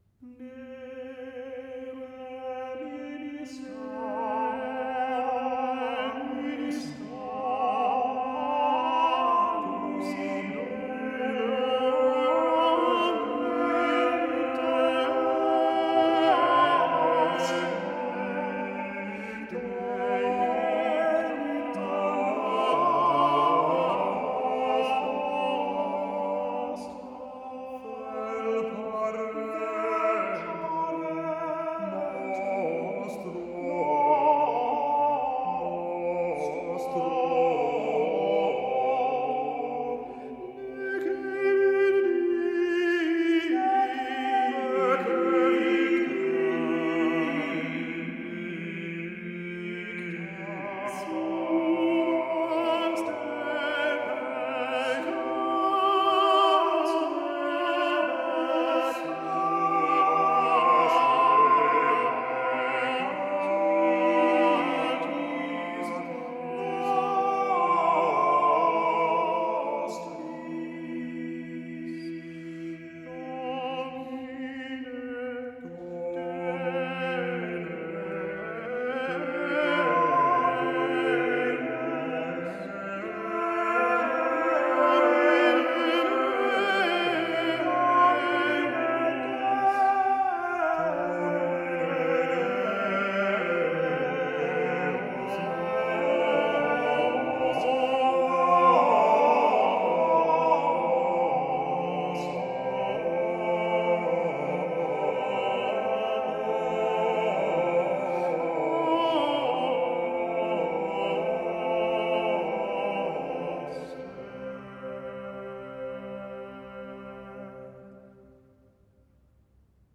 Francisco de Peñalosa : Bruno Turner & Pro Cantione Antiqua
Their style is understated but very expressive.  The male OVPP group sings Renaissance polyphony with clarity and beauty.